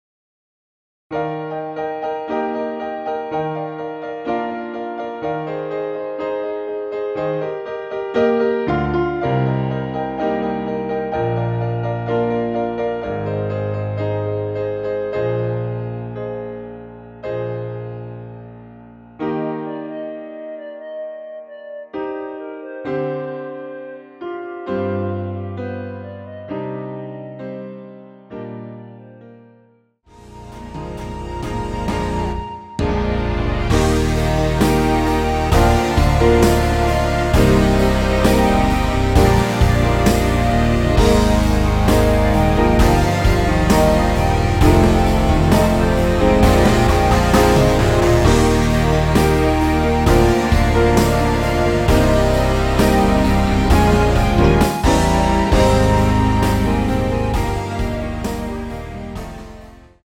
원키에서(-3)내린 멜로디 포함된 MR입니다.(미리듣기 확인)
Eb
앞부분30초, 뒷부분30초씩 편집해서 올려 드리고 있습니다.
중간에 음이 끈어지고 다시 나오는 이유는